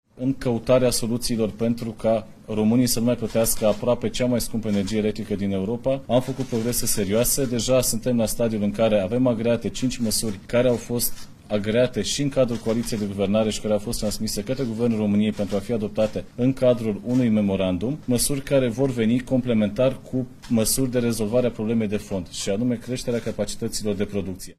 Bogdan Ivan, ministrul Energiei: „Suntem la stadiul în care avem agreate cinci măsuri”